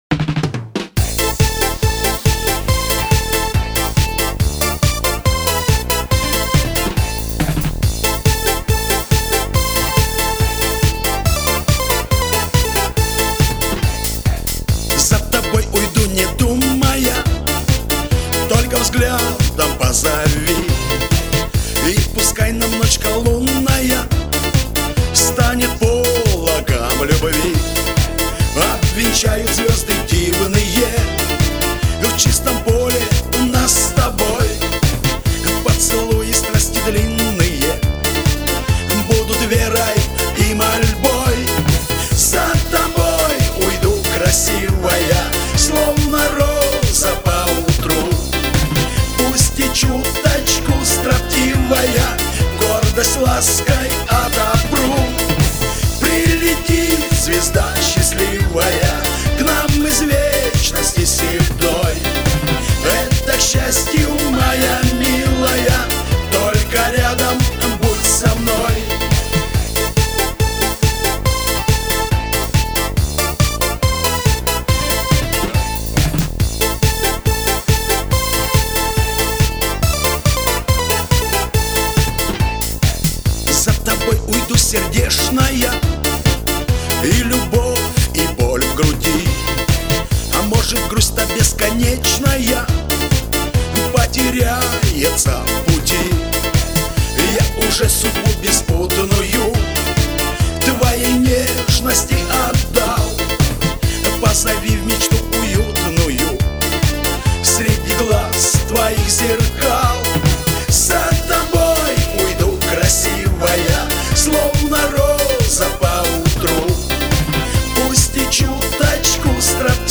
Категория: Шансон 2016